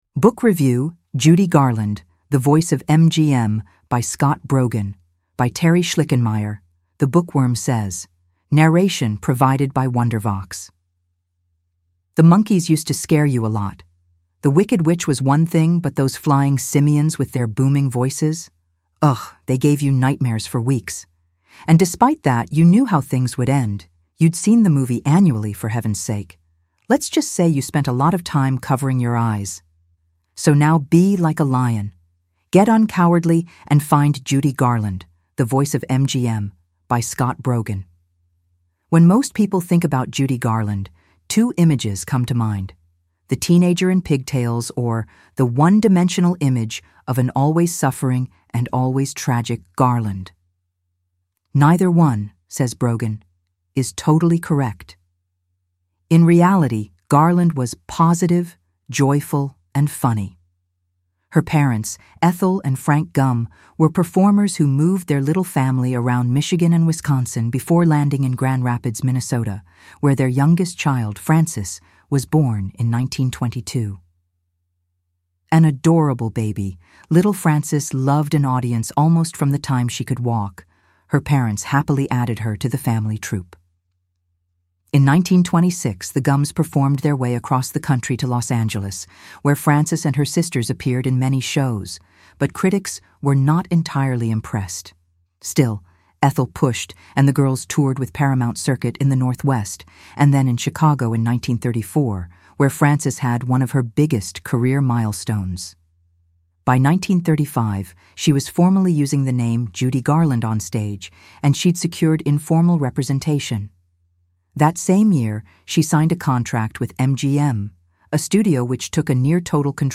Narration provided by Wondervox.